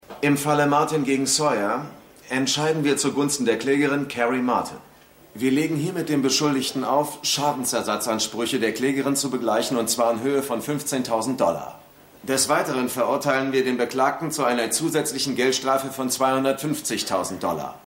LALAW_4x10_Obmann.mp3